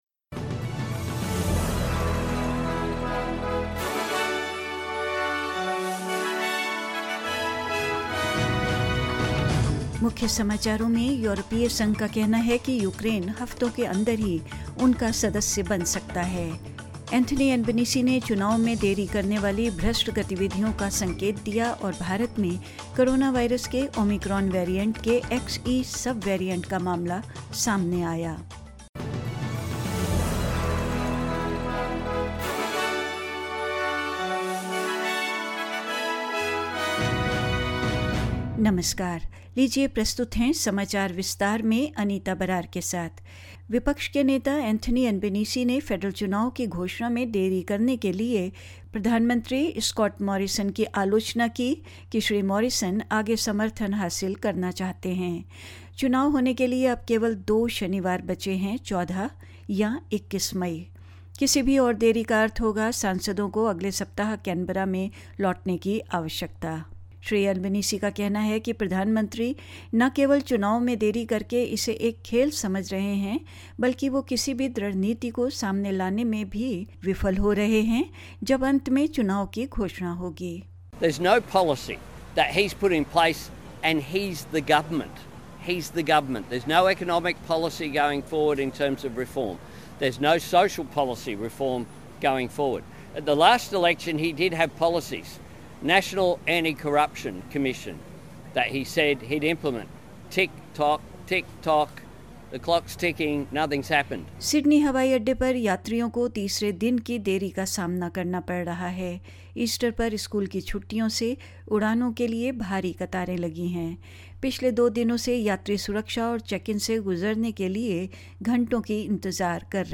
In this latest SBS Hindi bulletin: The E-U says Ukraine could become a member within weeks; Antony Albanese hints at corrupt activities delaying the election; Omicron’s new sub-variant XE detected in different parts of the India and more news.